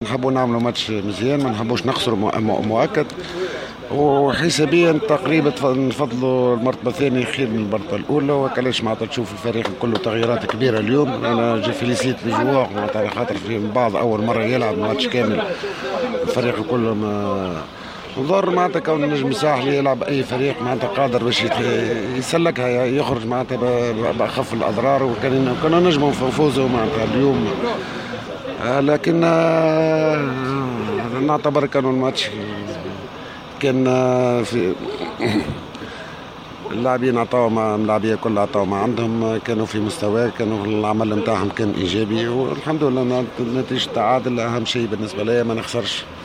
أكد مدرب النجم الساحلي فوزي البنزرتي في تصريح لمبعوث جوهرة أف أم إلى المغرب ، إثر نهاية اللقاء الذي جمع فريقه بالفتح الرباطي المغربي في إطار الجولة الأخيرة من دور المجموعات لكأس الكاف ، أن التغييرات التي شهدها الفريق اليوم قد أظهرت أن النجم الساحلي يملك رصيدا ثريا من اللاعبين القادرين على التحكم في المقابلات الكبرى مشيرا أنه حسابيا أراد الفريق إنهاء المرحلة في المرتبة الثانية.